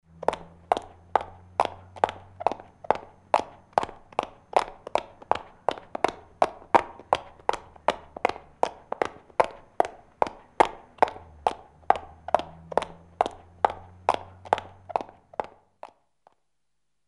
急促的高跟鞋1.mp3
通用动作/01人物/01移动状态/高跟鞋/急促的高跟鞋1.mp3
• 声道 立體聲 (2ch)